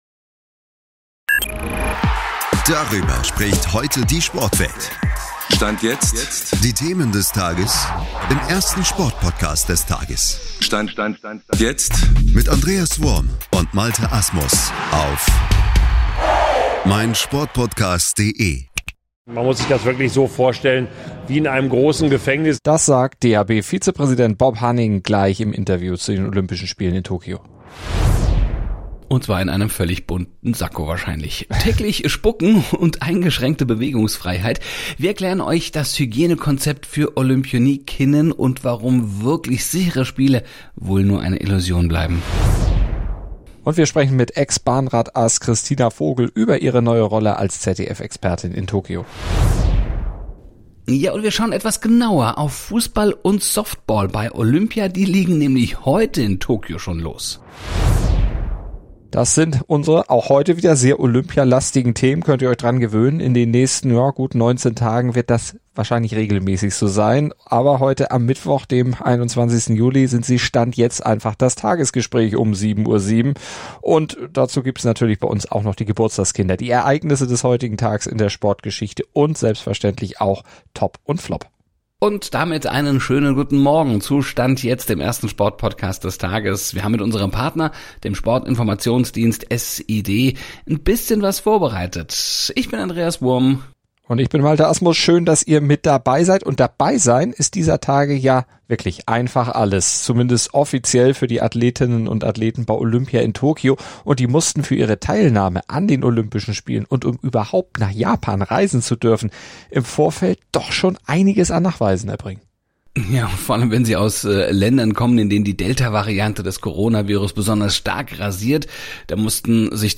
Und sie nehmen sich dieser Themen des Sporttages an: Bob Hanning spricht im SID-Interview über die deutschen Medaillenambitione Ex-Bahnrad-Ass Kristina Vogel spricht über ihre neue Rolle als ZDF-Expertin in Tokio Und mit Fußball und Softball geht es heute schon bei Olympia los Dazu gibt es natürlich Geburtstagskinder, die Ereignisse des heutigen Tags in der Sportgeschichte und Top und Flop ...